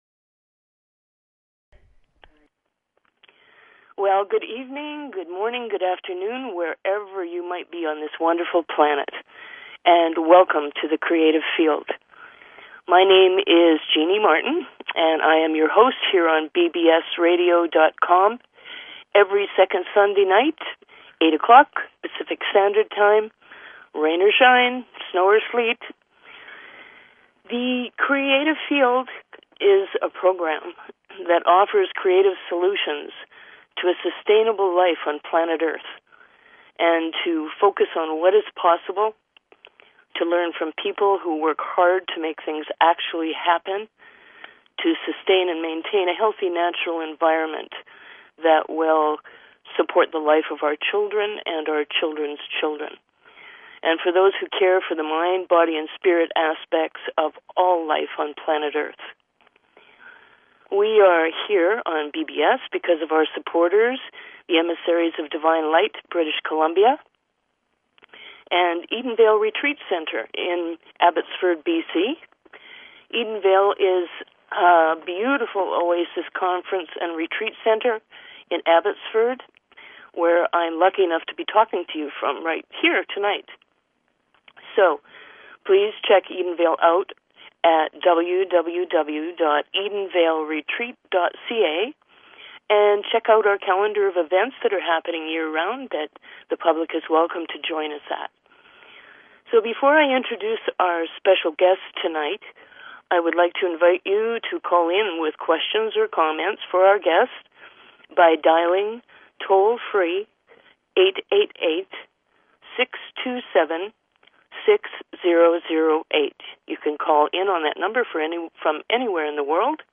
Talk Show Episode, Audio Podcast, The_Creative_Field and Courtesy of BBS Radio on , show guests , about , categorized as